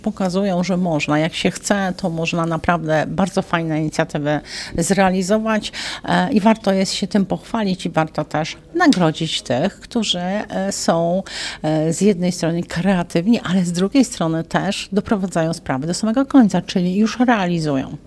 Członkini zarządu województwa mazowieckiego, Janina Ewa Orzełowska mówi, że w ten sposób organizatorzy konkursu chcą promować dobre praktyki, aby koła mogły się od siebie nawzajem uczyć i podpatrywać ciekawe pomysły.